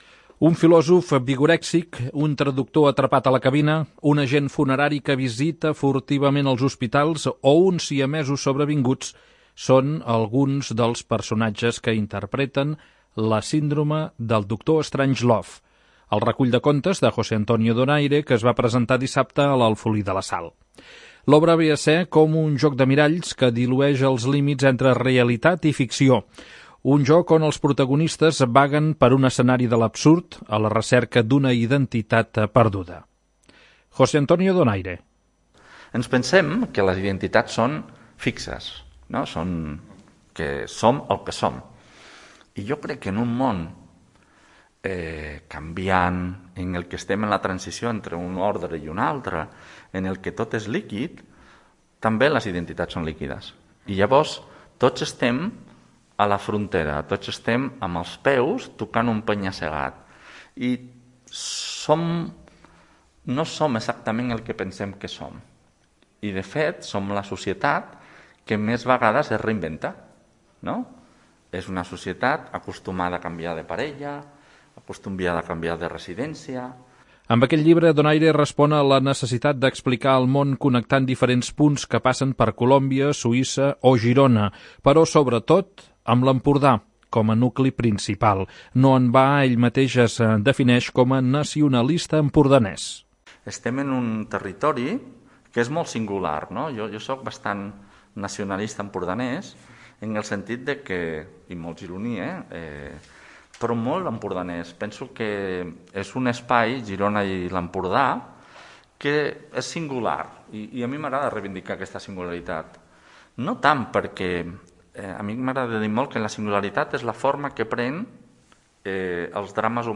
La presentació es va fer a la sala d'actes de l'Alfolí de la Sal davant una quinzena d'assistents.